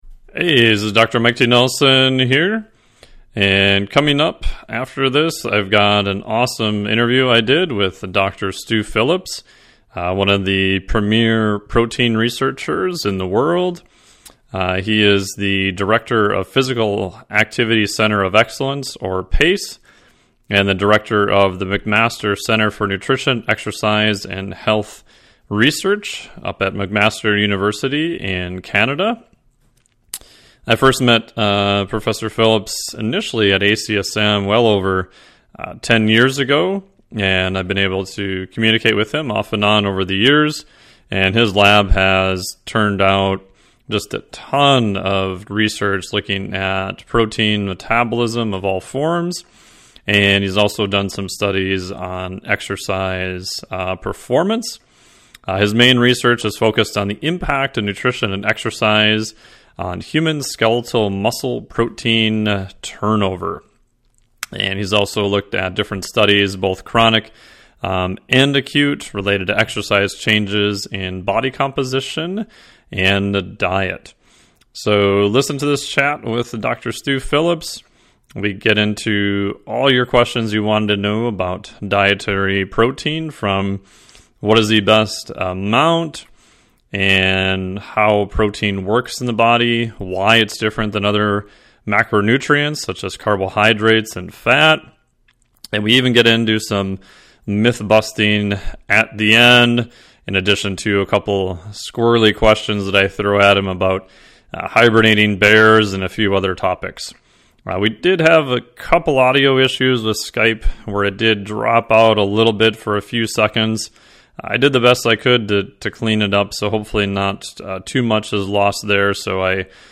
Audio of Interview